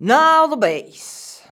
NOW BASS.wav